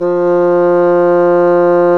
Index of /90_sSampleCDs/Roland L-CDX-03 Disk 1/WND_Bassoons/WND_Bassoon 2
WND BASSOO0A.wav